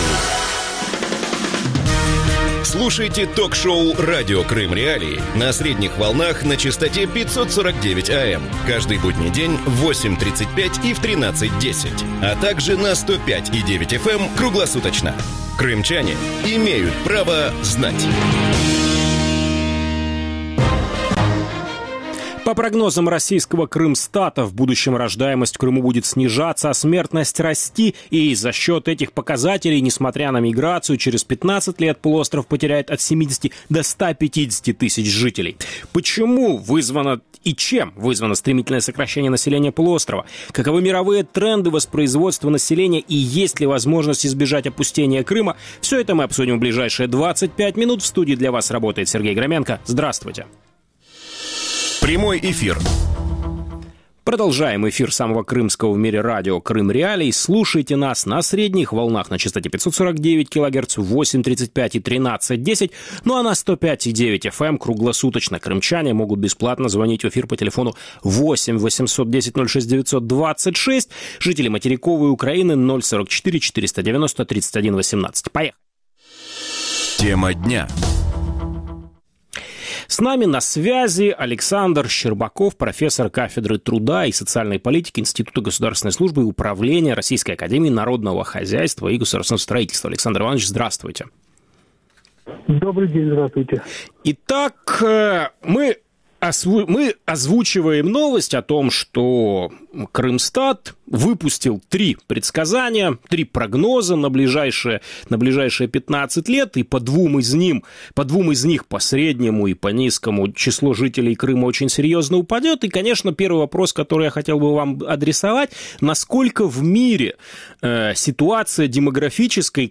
Чем вызвано сокращение населения полуострова? Каковы мировые тренды воспроизводства населения? И есть ли возможность избежать опустения Крыма? С 12.10 до 12.40 в эфире ток-шоу Радио Крым.Реалии